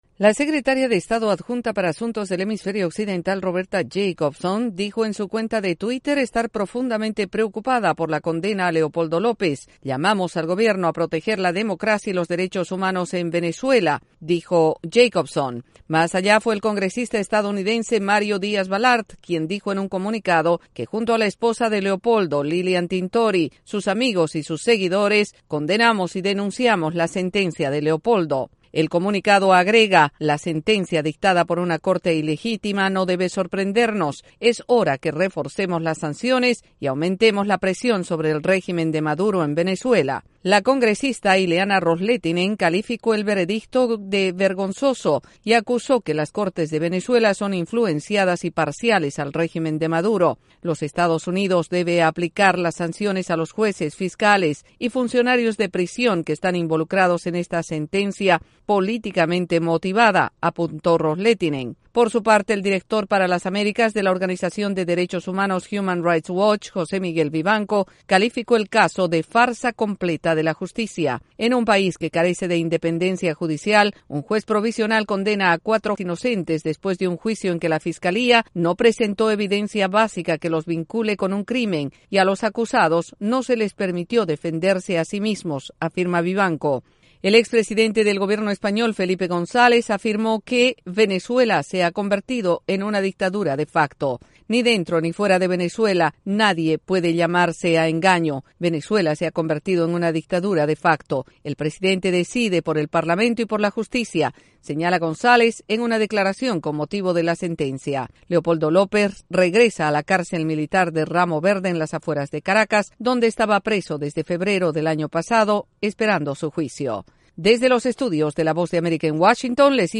Surgen varias reacciones a la condena impuesta a Leopoldo López en Venezuela. Desde la Voz de América en Washington